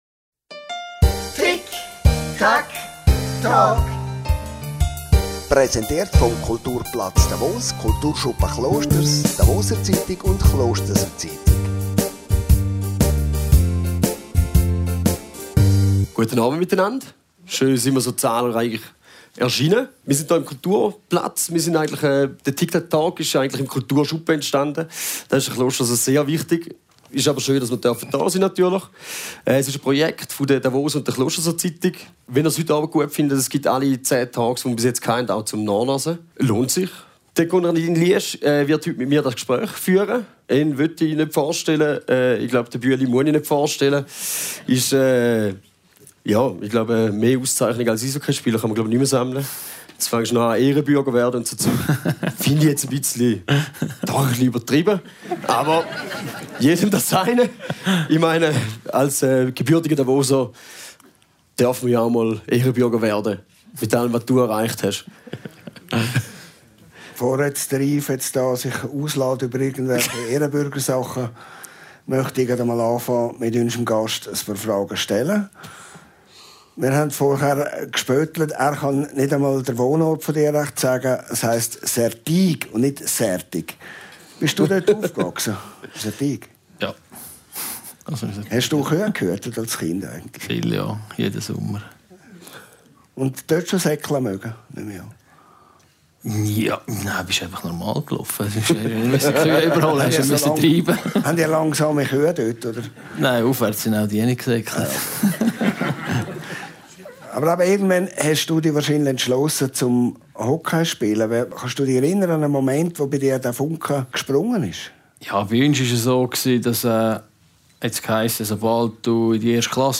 Talkshow und Podcast, präsentiert von der «Davoser Zeitung», Kulturplatz Davos, der «Klosterser Zeitung» und dem Kulturschuppen Klosters. Gast ist Hockeylegende Andres Ambühl.